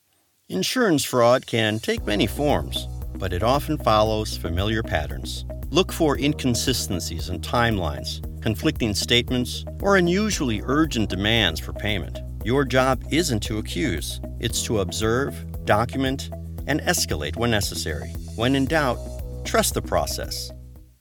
His delivery is warm, steady, and approachable, making even complex material feel manageable.
E- Learning Demos